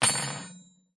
餐具声音 " 大叉子4
Tag: 餐具